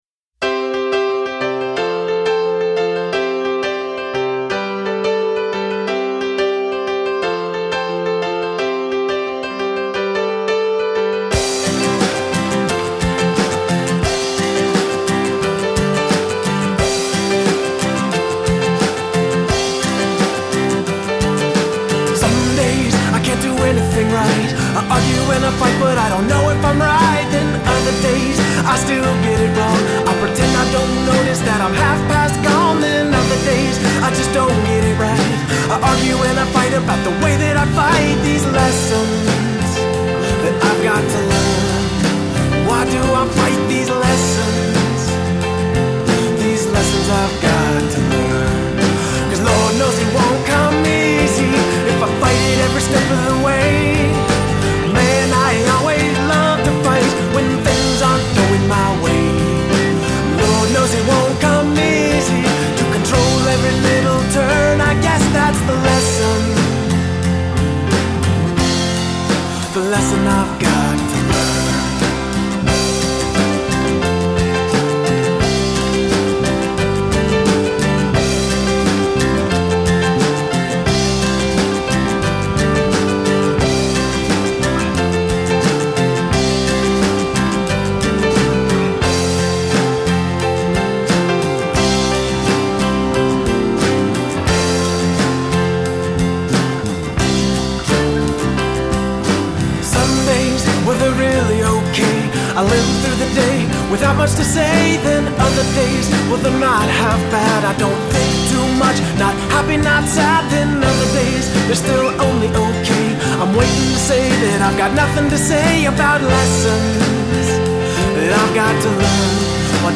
Guitar, Vocals
Drums
Bass Guitar
Piano, Organ